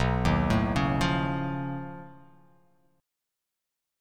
B9b5 Chord